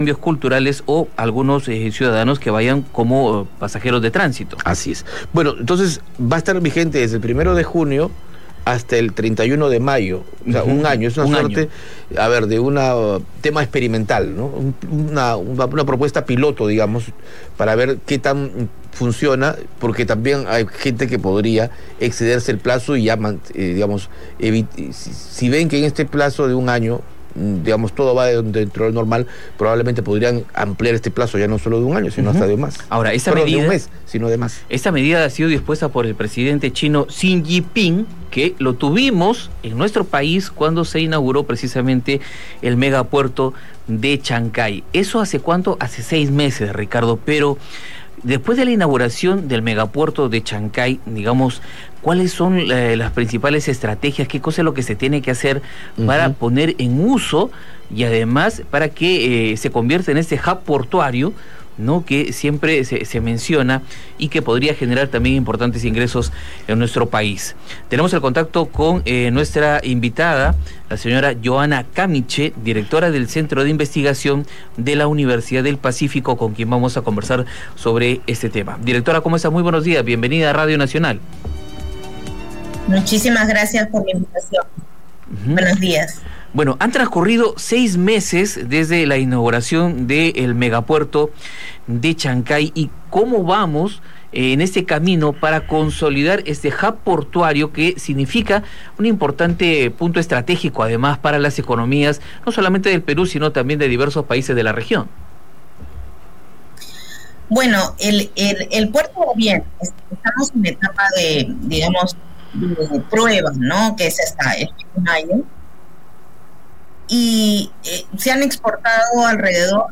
Entrevista.